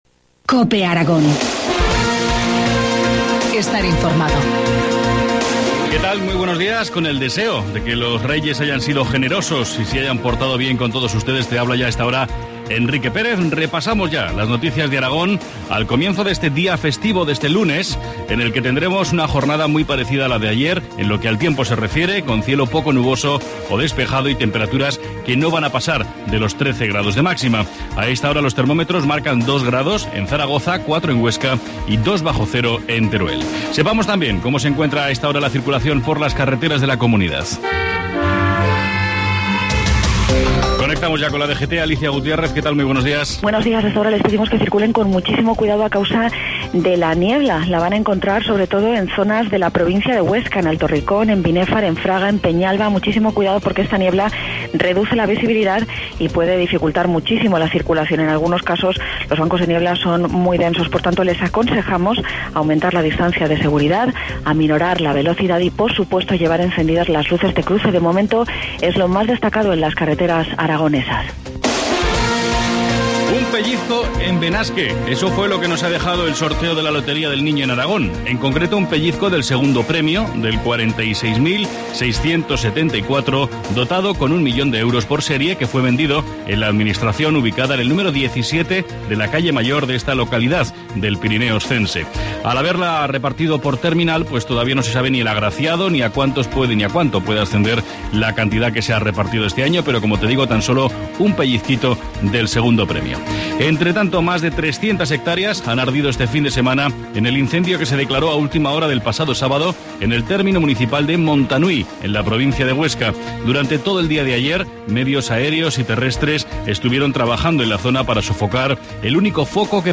Informativo matinal, lunes 7 de enero, 7.25 horas